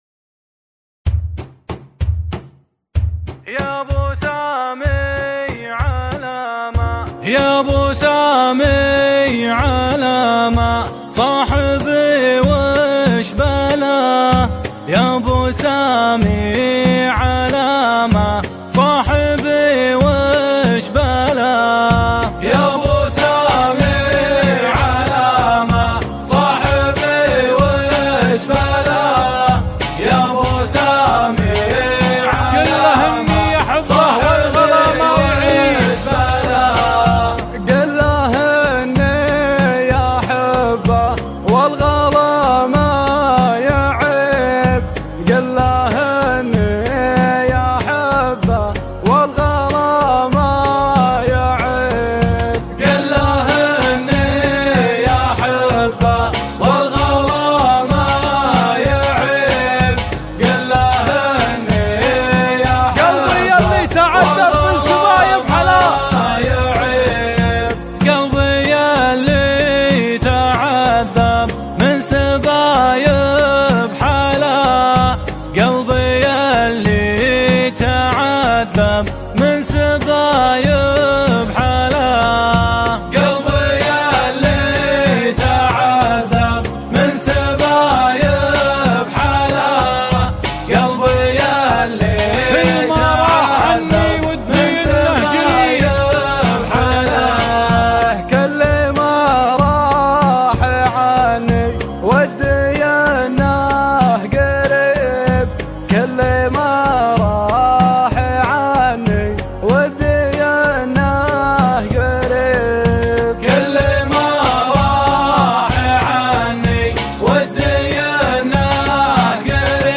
أغاني الشيلات